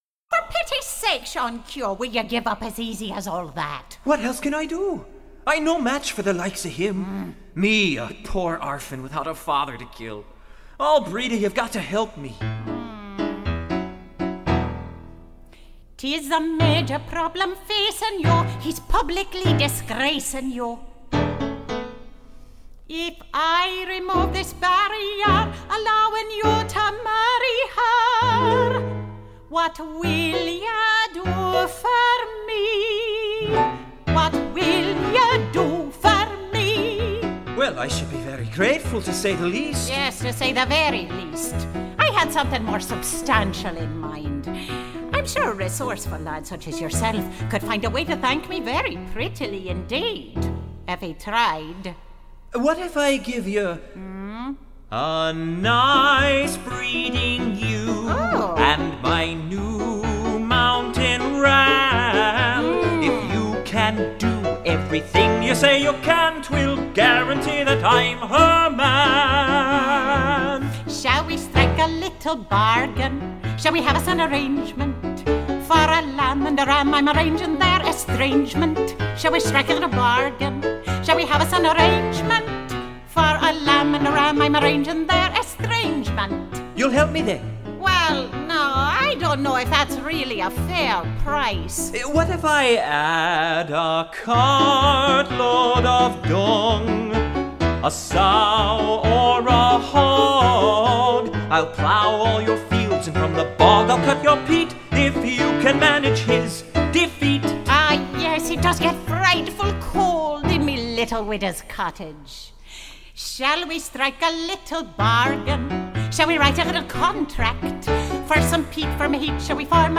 - An Irish Musical Comedy
(Widow, Sean)